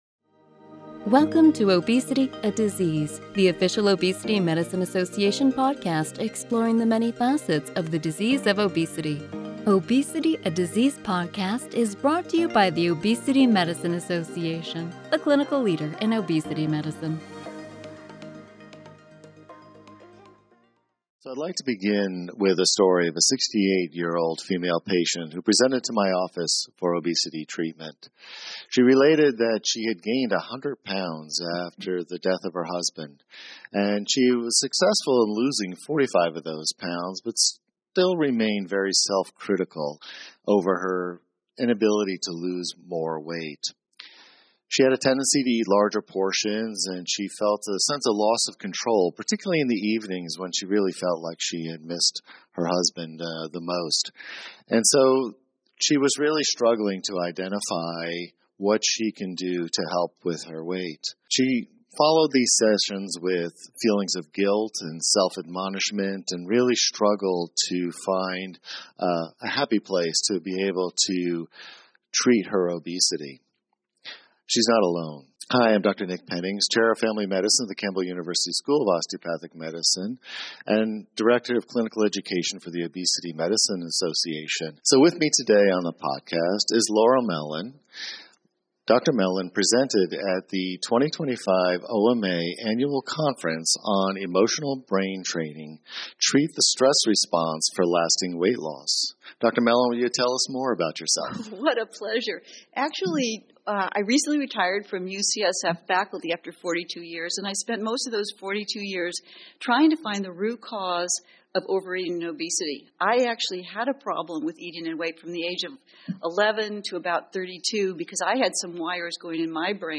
Episode 114: Clinical Conversations: Emotional Brain Training